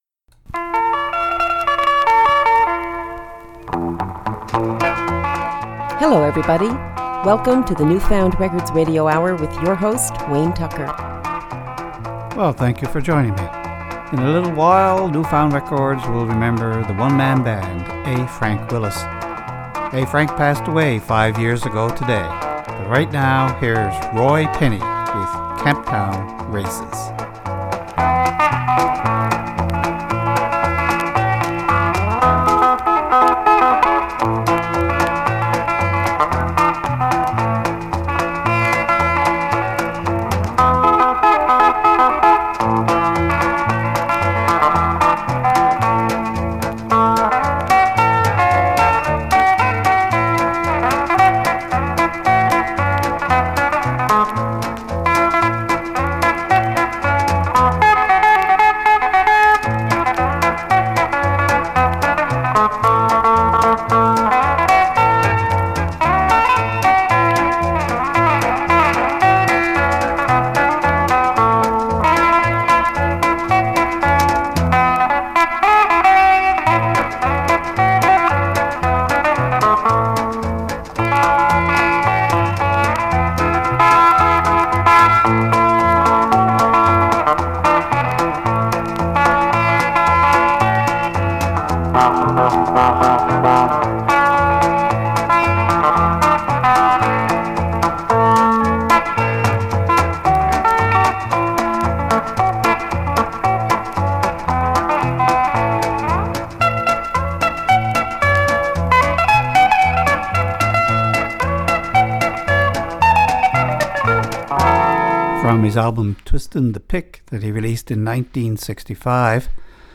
Recorded at CHMR studios, MUN, to air Feb 27, 2016.